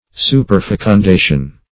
Superfecundation \Su`per*fec`un*da"tion\, n. (Physiol.)